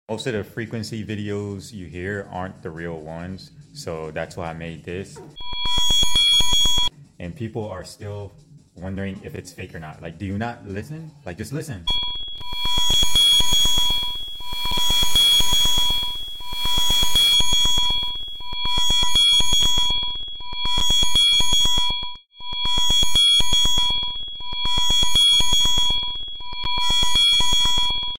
meditation. frequencies. frequency. frequency videos. sound effects free download